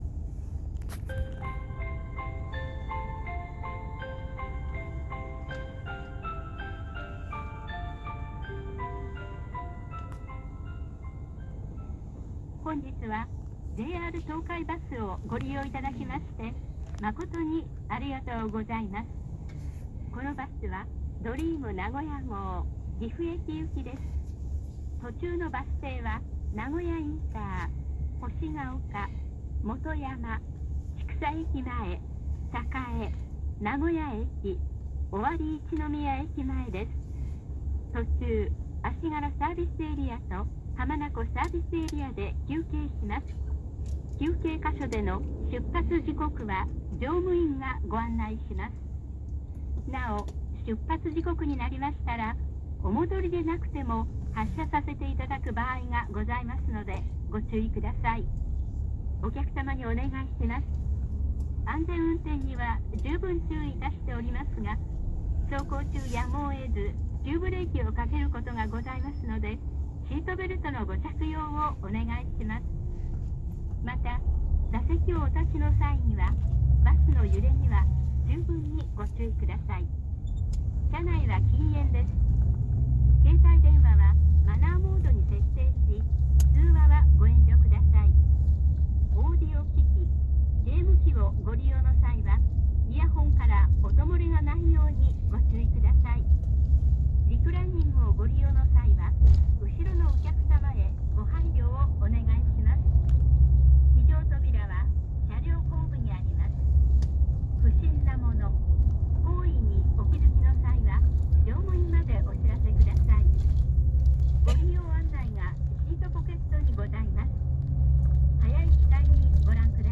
ドリーム名古屋・岐阜号（岐阜行き）東京駅出発後放送